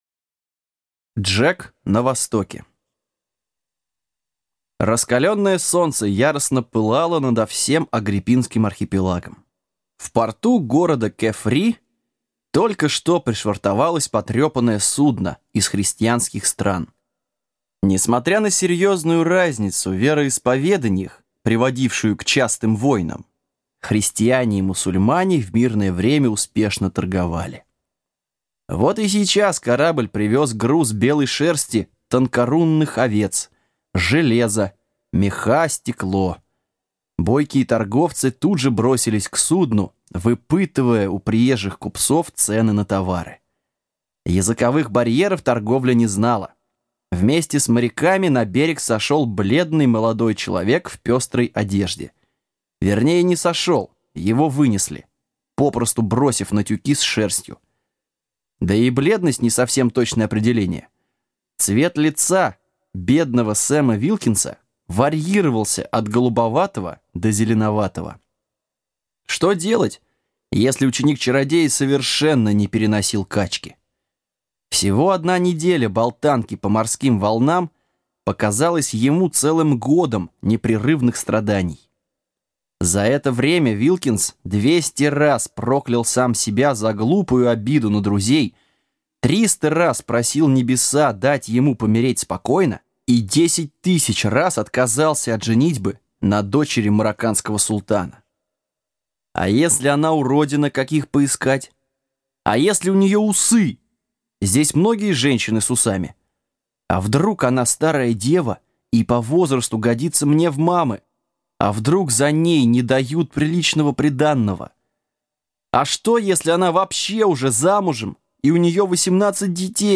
Аудиокнига Джек на Востоке | Библиотека аудиокниг